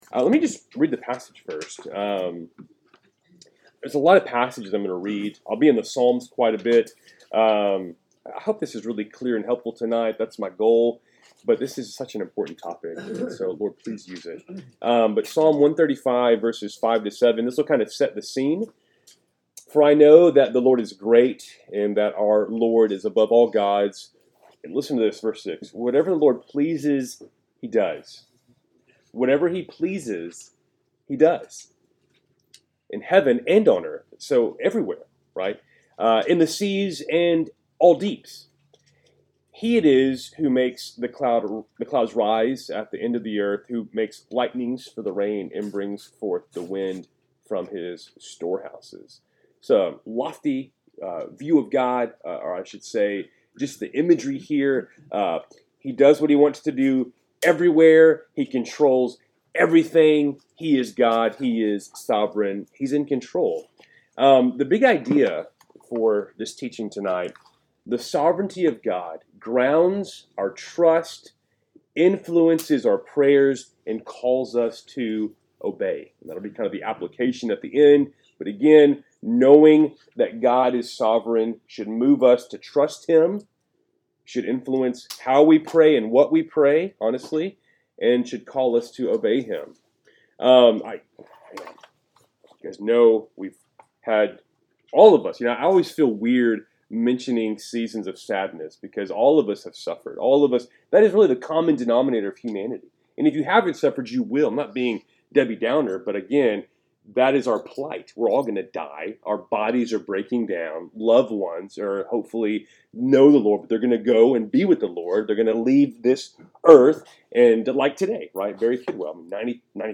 Wednesday Night Bible Study, December 4, 2024